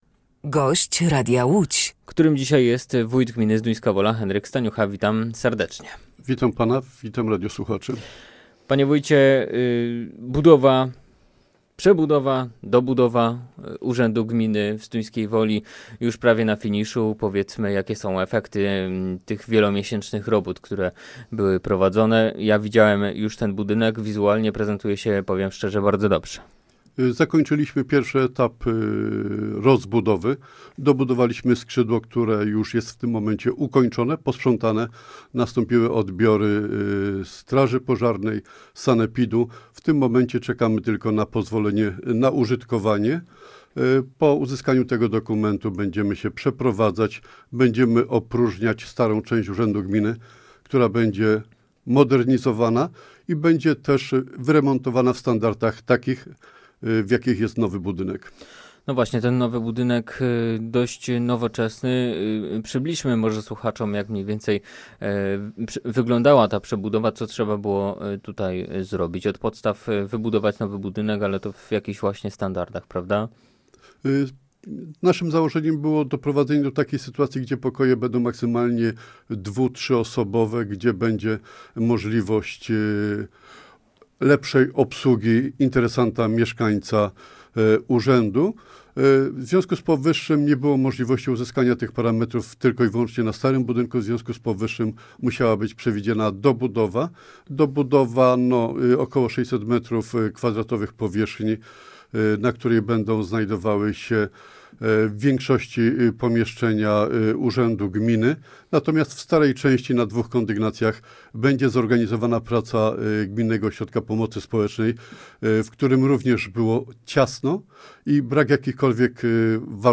Gościem Radia Łódź Nad Wartą był wójt, gminy Zduńska Wola, Henryk Staniucha.